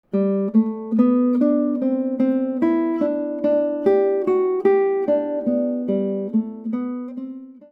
The ritornello of Jesu, Joy of Man's Desiring consists of flowing quarter notes.
Jesu, Joy of Man's Desiring (first line) | The pickup notes begin on beat 2.
Jesu_joy_man_GTR_melody_ex.mp3